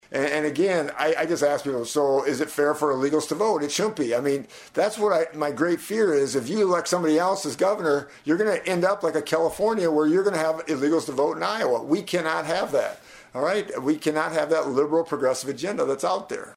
FEENSTRA MADE HIS COMMENTS DURING AN INTERVIEW AT KSCJ.